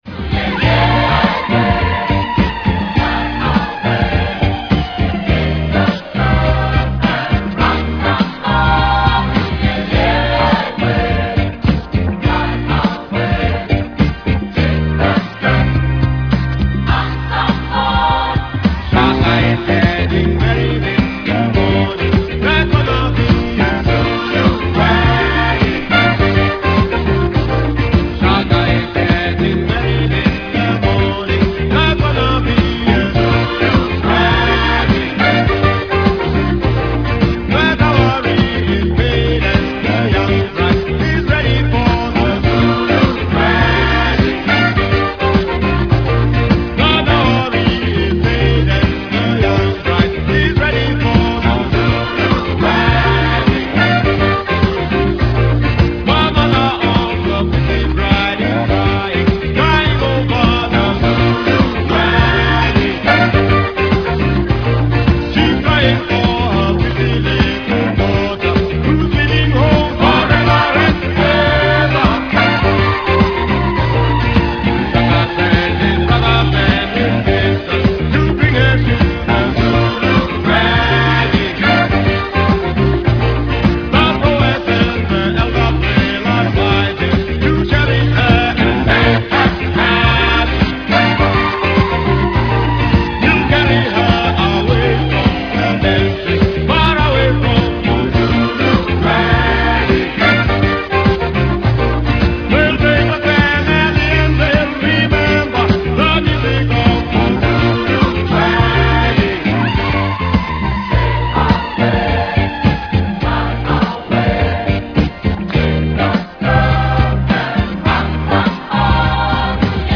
wedding song